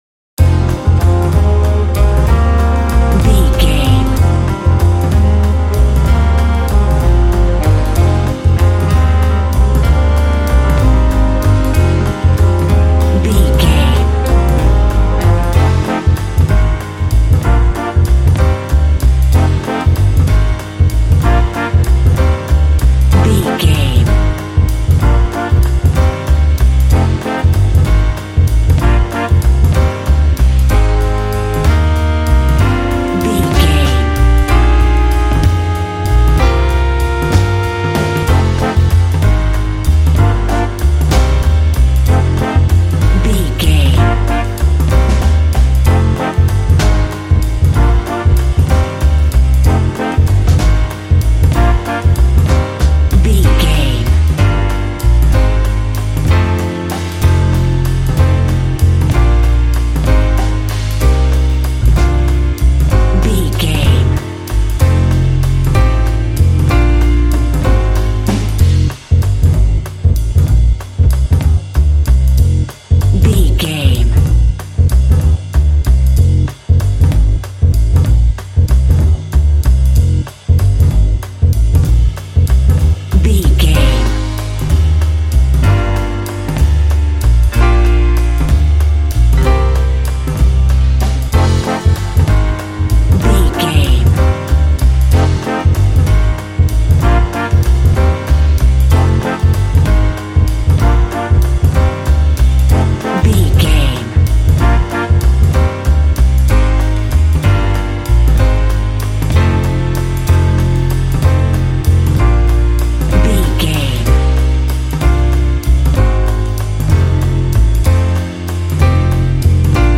Smooth jazz track reminiscent of classic jazz standards.
Aeolian/Minor
melancholy
smooth
saxophone
double bass
drums
piano
swing